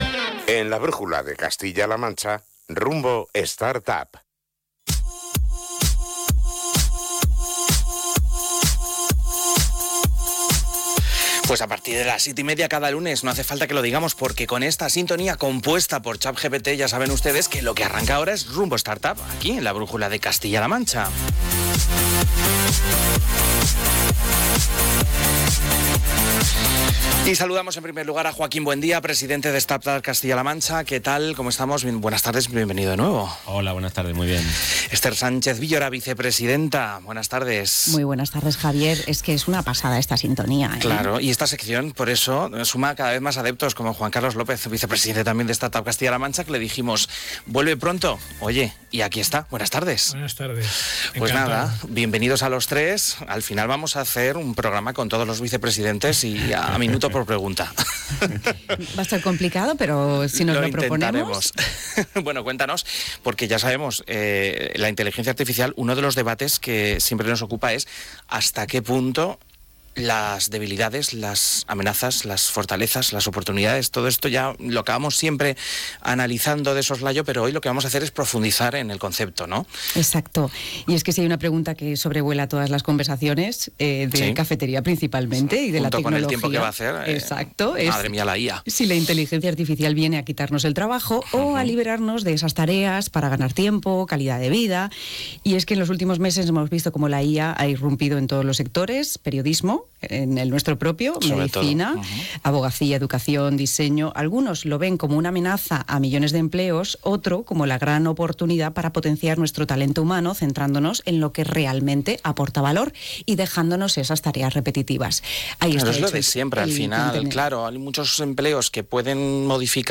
¿Sabías que la pegadiza sintonía de «Rumbo Startup» la ha compuesto una IA?
El debate estuvo candente, aunque nadie salió quemado.